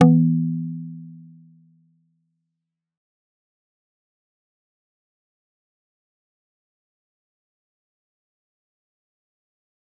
G_Kalimba-E3-f.wav